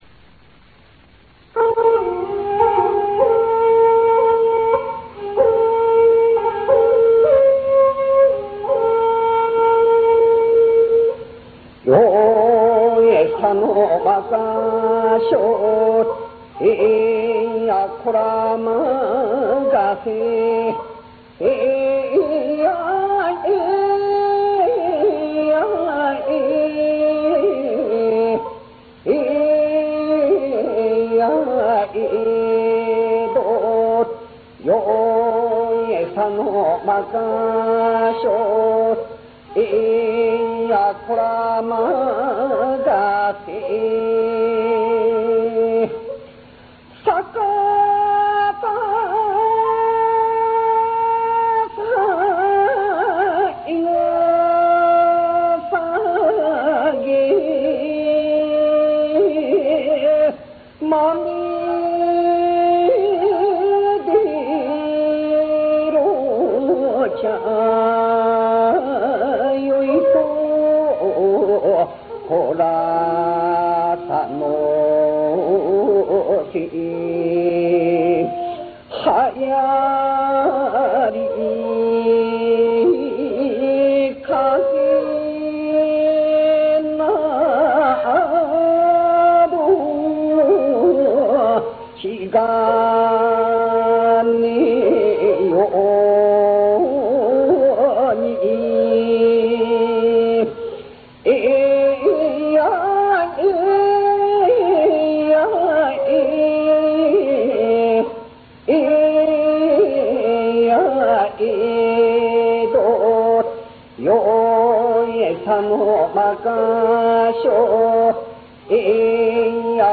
最上川舟歌　　団体客で混みあうことが多いという観光船も、この日舟に乗りあわせた客は、たったの４人、２人の船頭のうちの案内役の一人は、「今日はマイクなしで大サービス」と、舟の真中に座りこんで、雑談風に説明をし、渋いのどを聞かせてくれた（最上川舟歌が聴けます、次をクリック
funauta4.ra